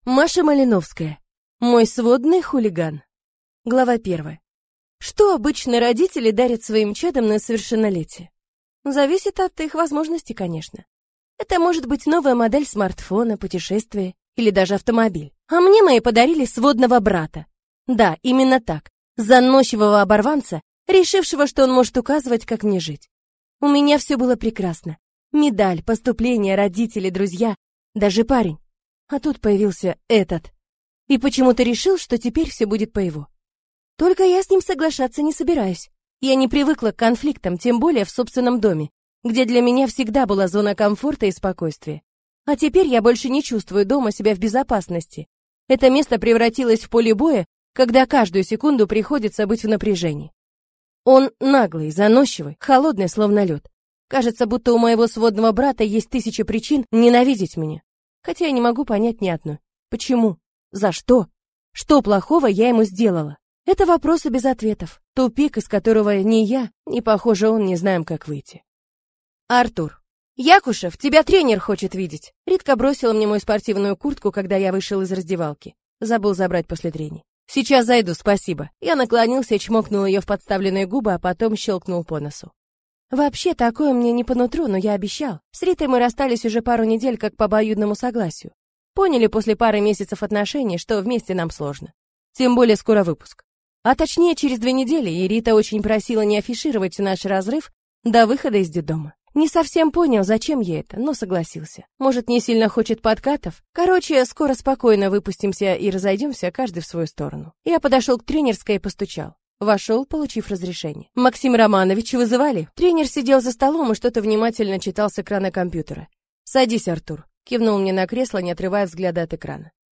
Аудиокнига Мой сводный хулиган | Библиотека аудиокниг